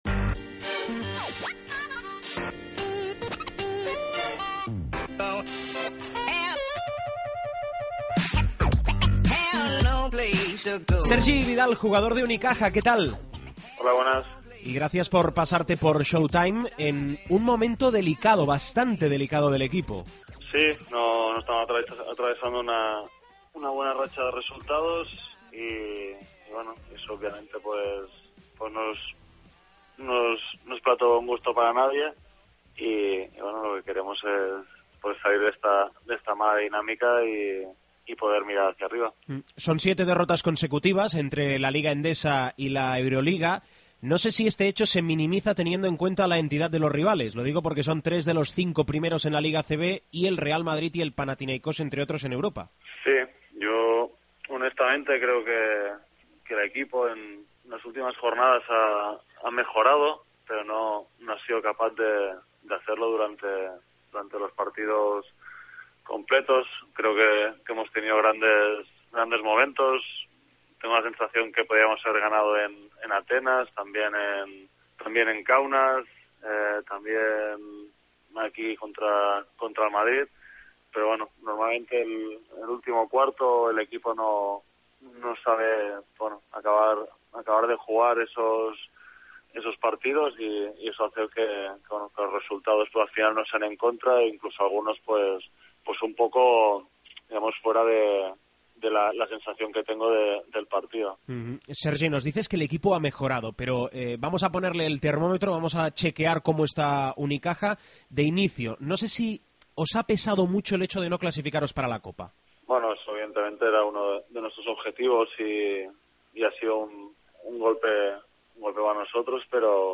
Entrevista a Sergi Vidal